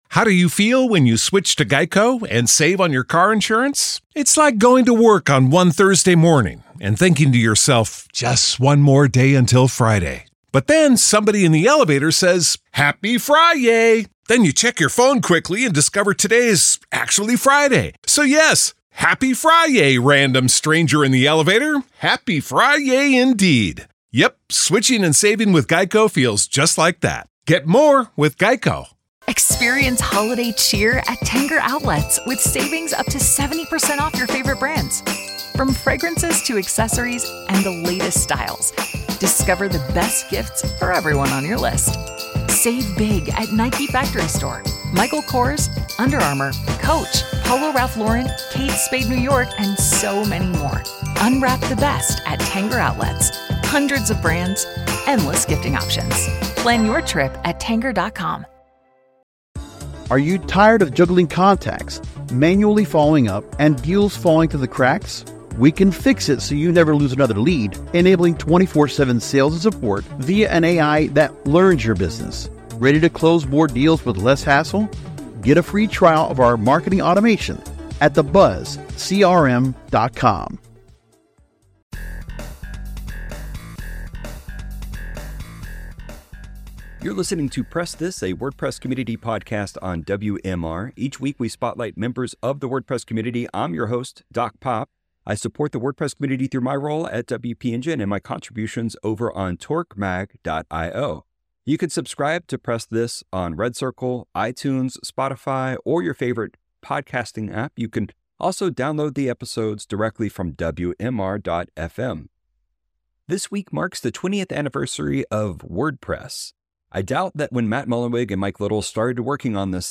AI's Place In A WordPress Agency Press This WordPress Community podcast